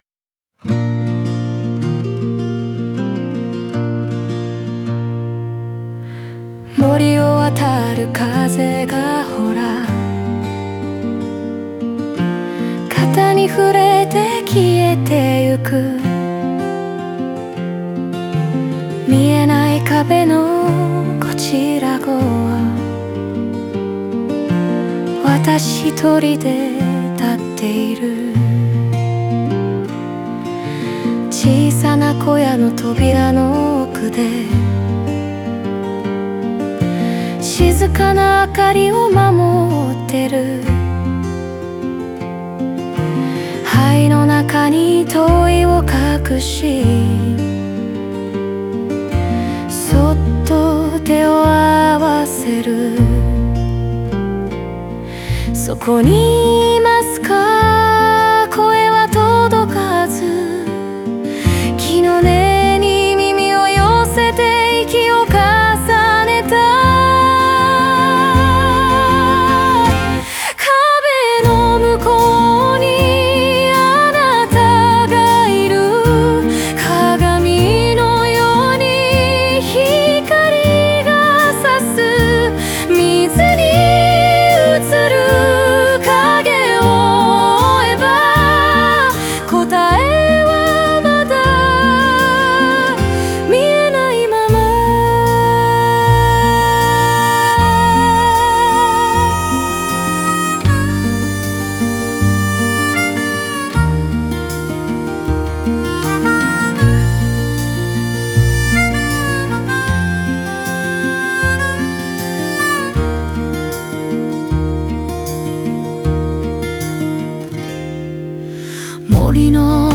歌い手は柔らかい語り口で、誰かに寄り添うように物語を紡ぎ、聴き手を「壁のむこう」へと導きます。
繰り返されるサビは祈りのように響き、孤立の中にも確かな温もりがあることを示唆します。